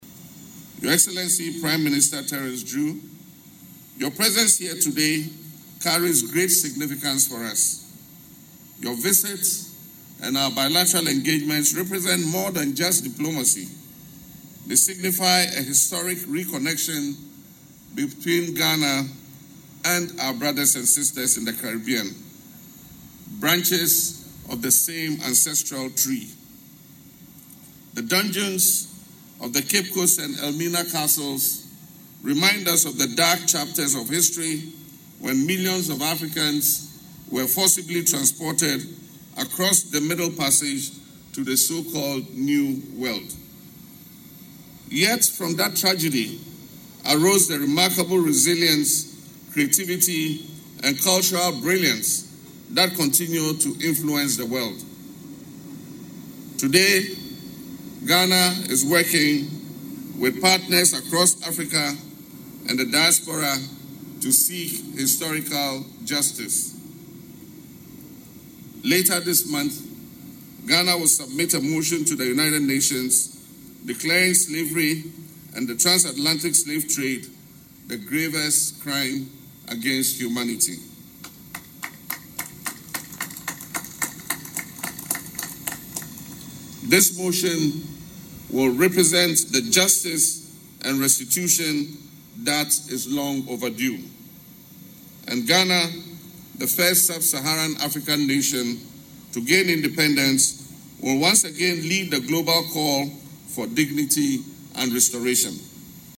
Delivering Ghana’s 69th Independence Day address, the President said the legacy of slavery remains an unhealed wound that must be addressed through justice and reparations.